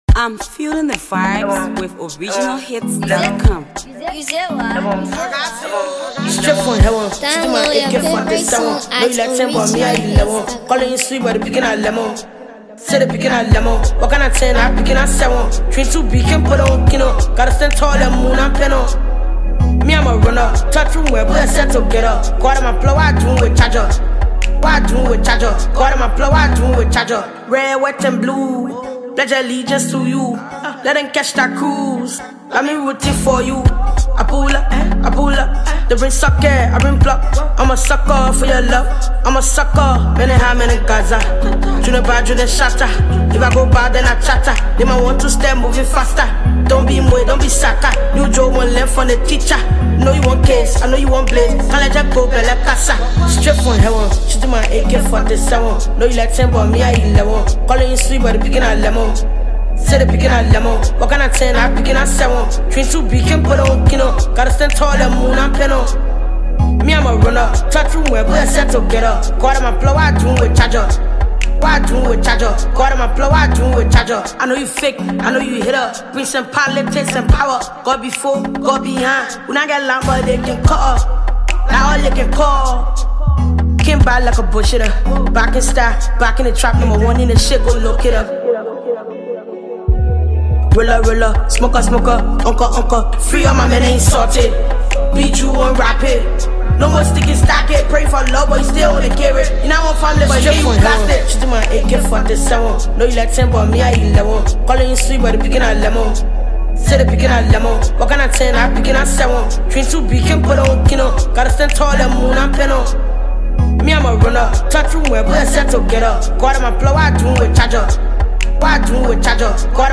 Freestyle
It’s a classical jam.